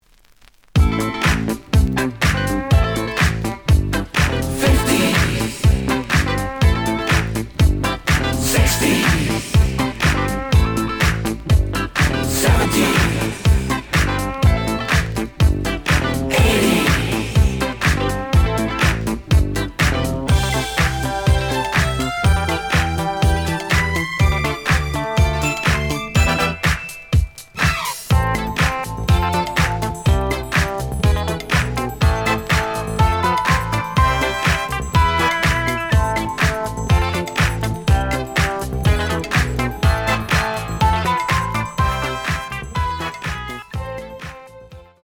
The audio sample is recorded from the actual item.
●Format: 7 inch
●Genre: Disco
Slight edge warp. But doesn't affect playing. Plays good.)